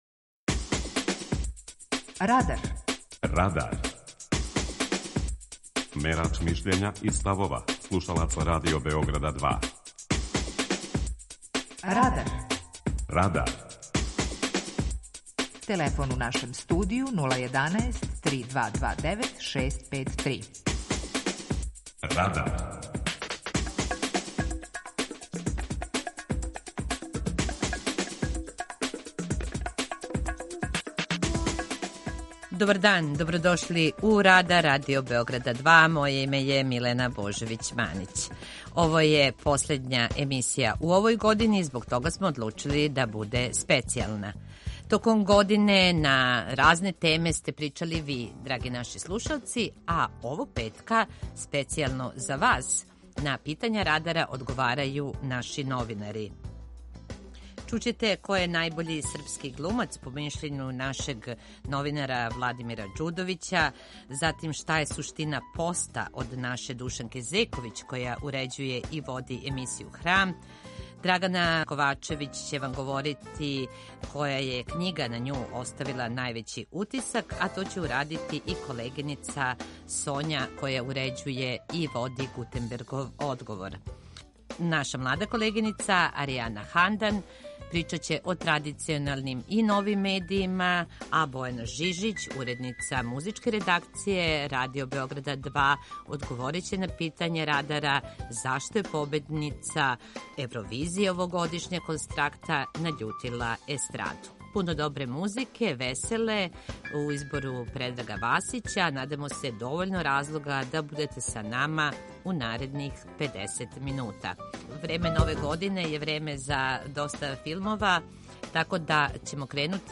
Новинари и уредници Радио Београда 2 одговарају на питања овогодишњег Радара.